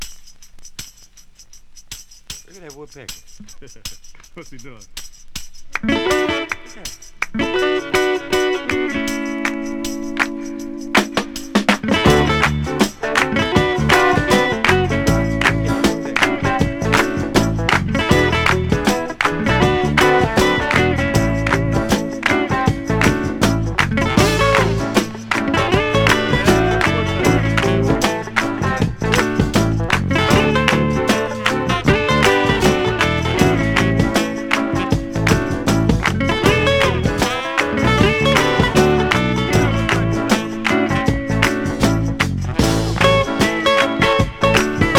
Rock, Rock & Roll　USA　12inchレコード　33rpm　Stereo